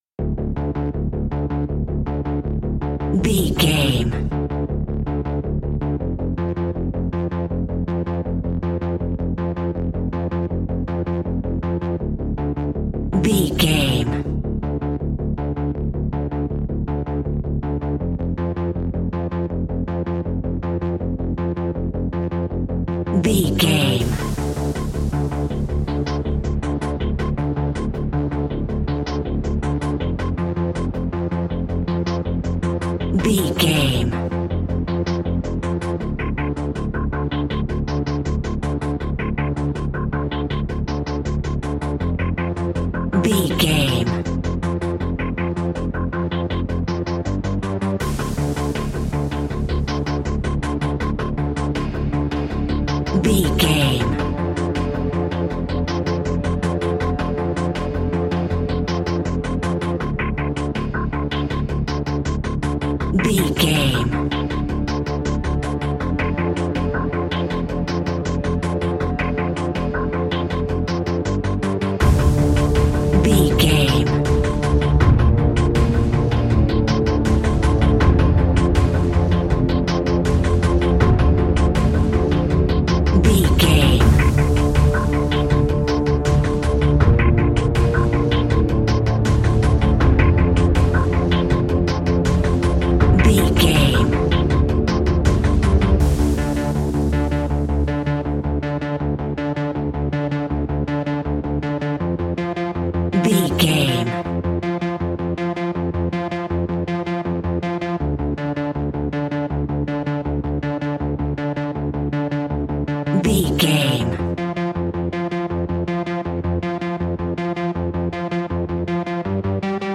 Aeolian/Minor
D
ominous
dark
eerie
synthesiser
strings
drums
percussion
horror music